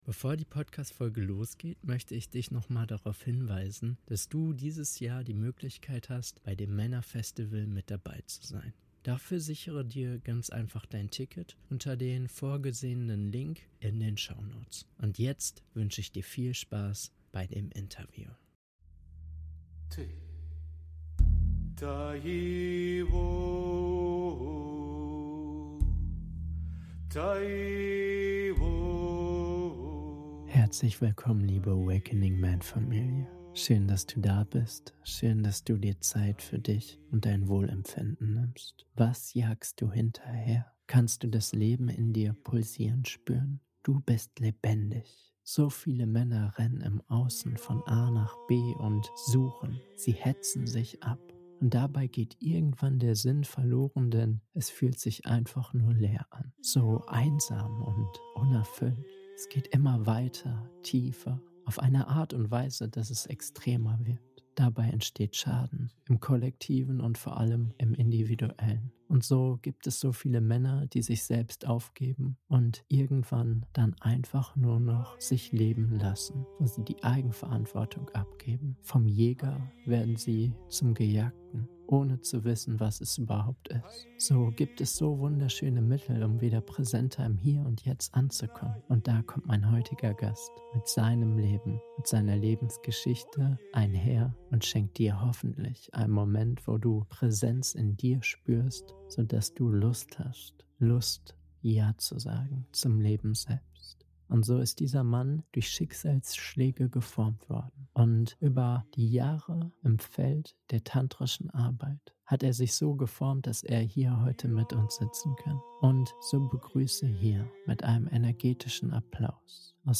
Einblicke ins Tantra - Interview